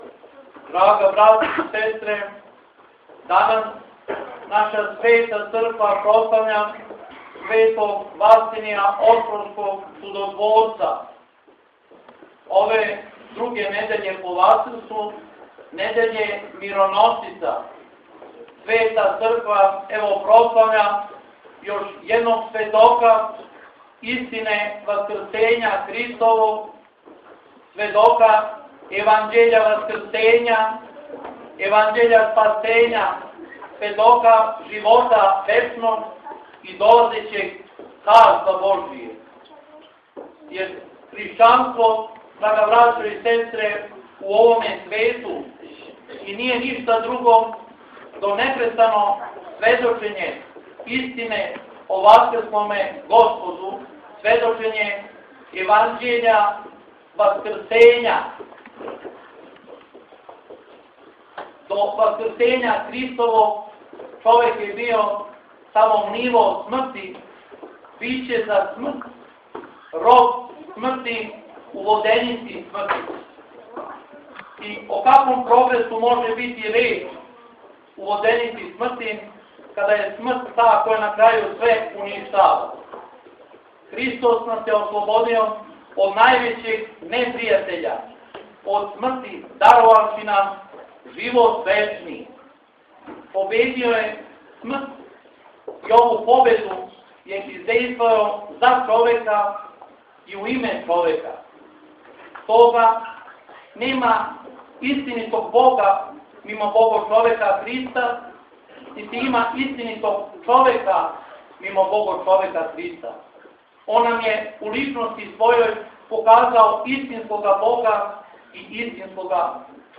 Бачко Добро Поље - Житељи Бачког Доброг Поља прославили су у четвртак, 12. маја 2011. године, славу свога храма и места – празник Светог Василија Острошког Чудотворца.
• Беседа